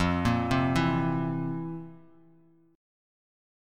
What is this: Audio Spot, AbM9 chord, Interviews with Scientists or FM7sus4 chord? FM7sus4 chord